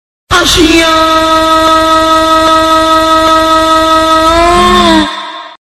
Efek suara Ashiaaap…
Kategori: Suara viral
Keterangan: Suara ini menjadi populer di media sosial karena kemampuannya untuk menciptakan suasana lucu dan menarik perhatian dalam setiap konten yang disajikan.
efek-suara-ashiaaap-id-www_tiengdong_com.mp3